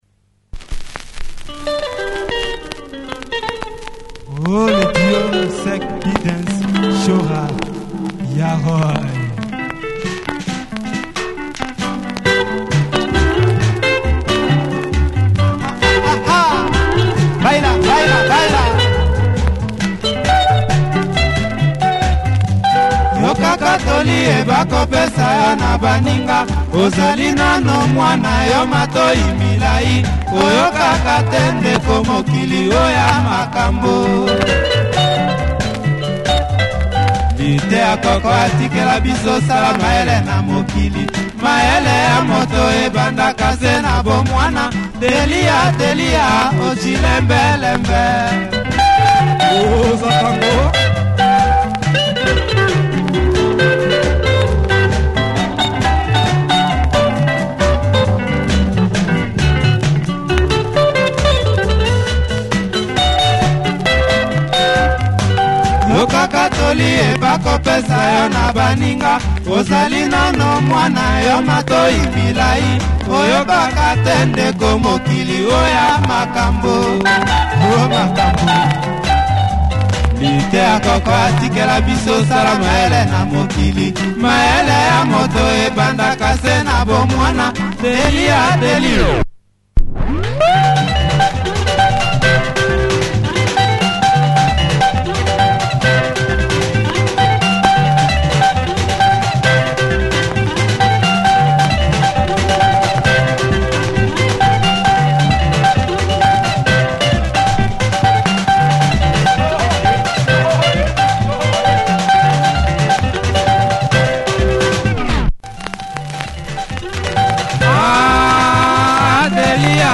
Classic example of Congo in Kenya.